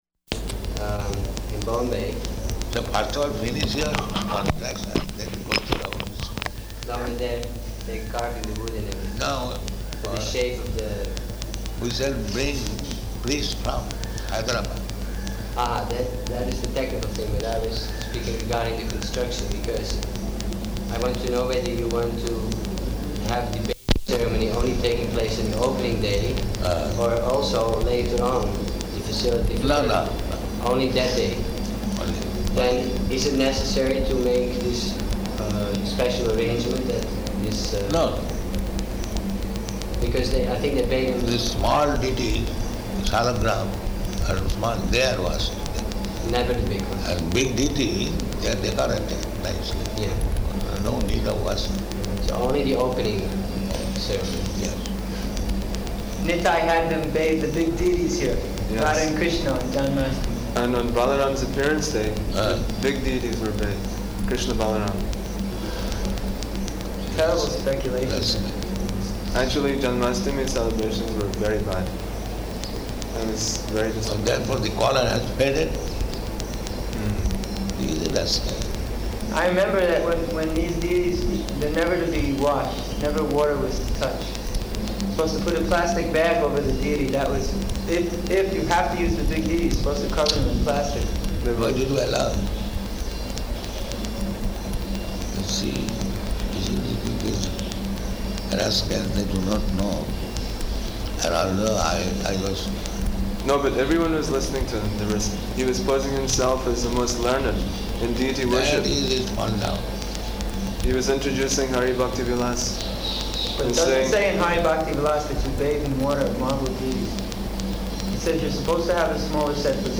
Room Conversation
-- Type: Conversation Dated: September 11th 1976 Location: Vṛndāvana Audio file